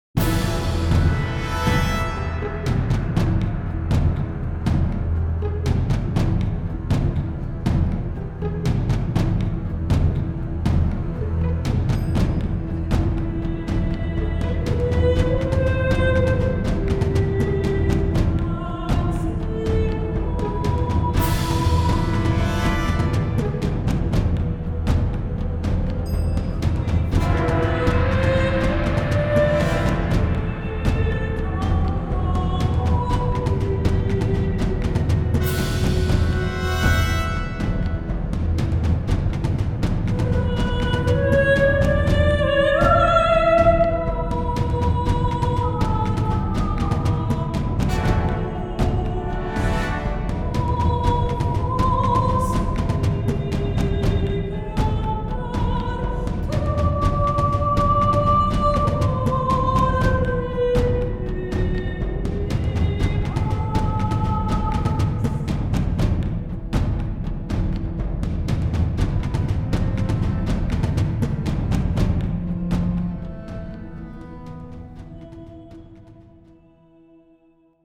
Musique de scène
la musique originale pour chœur et orchestre de chambre